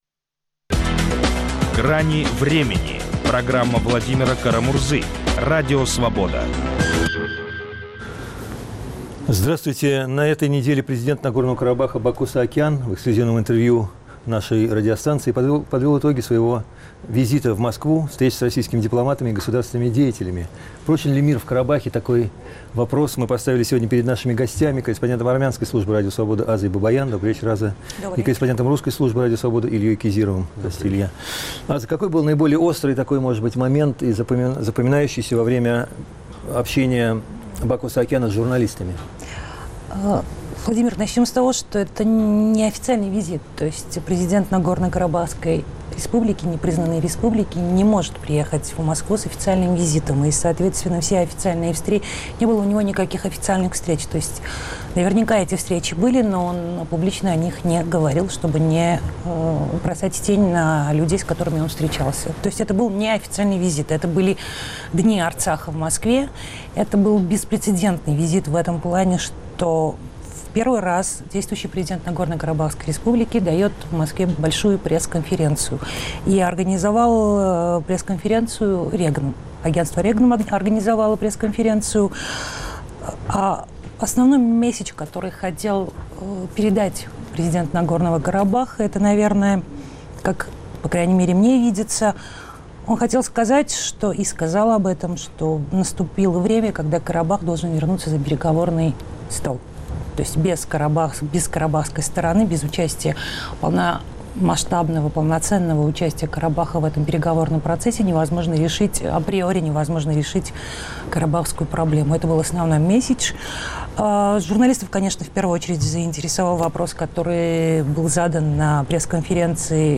Президент Нагорного Карабаха Бако Саакян в эксклюзивном интервью Радио Свобода подвел итоги своего рабочего визита в Москву, встреч с российскими дипломатами и государственными деятелями.